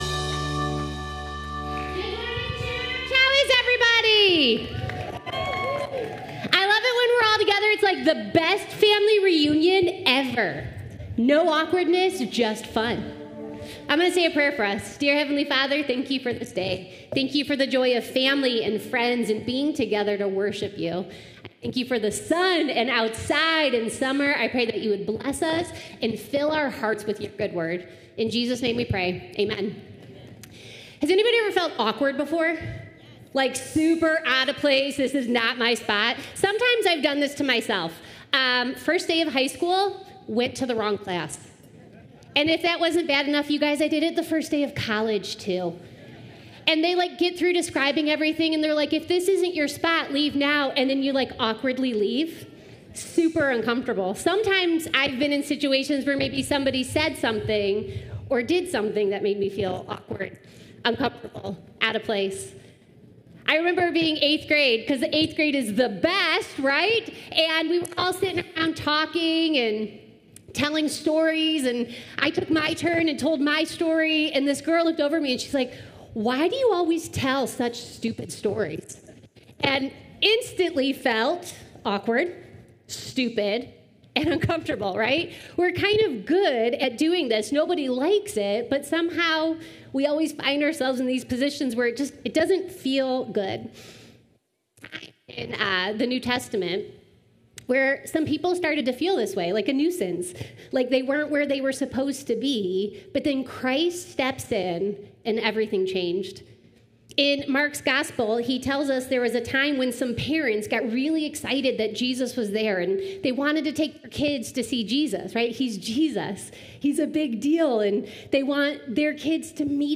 Columbia Station Campus (Unified Service)